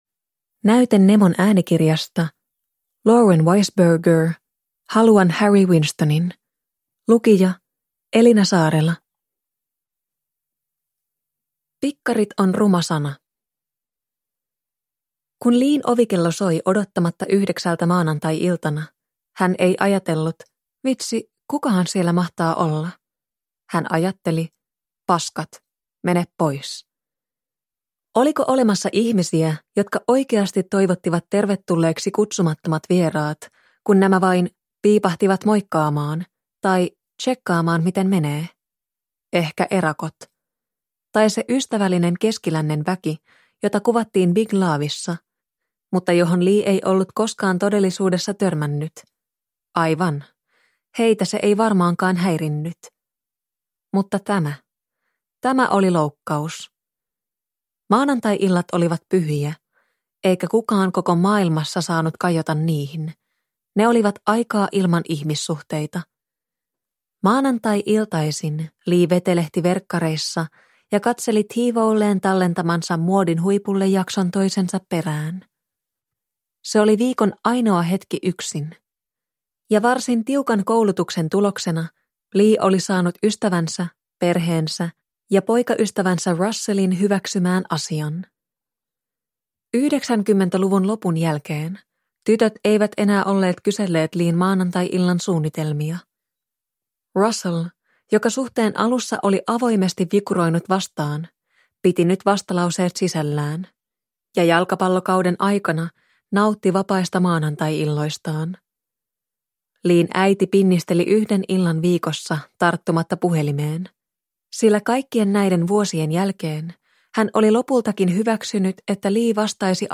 Haluan Harry Winstonin – Ljudbok – Laddas ner